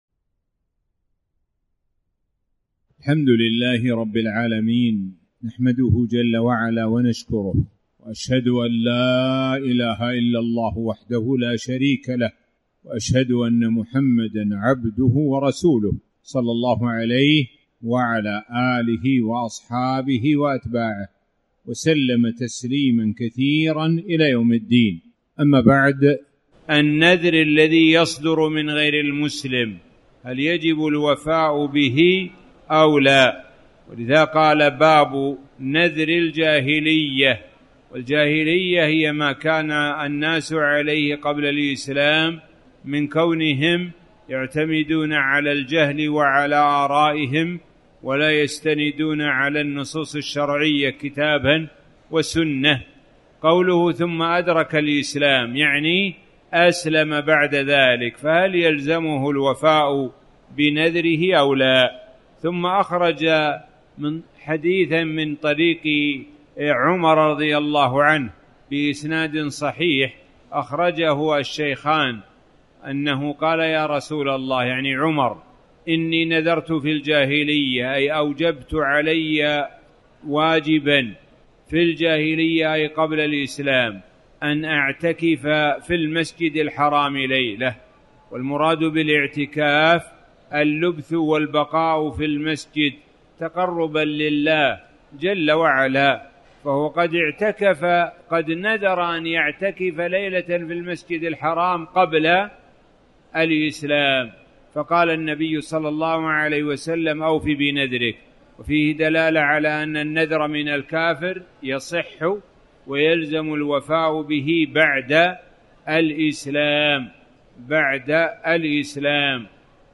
تاريخ النشر ٢٩ ذو القعدة ١٤٣٩ هـ المكان: المسجد الحرام الشيخ: معالي الشيخ د. سعد بن ناصر الشثري معالي الشيخ د. سعد بن ناصر الشثري باب من نذر أن يتصدق بماله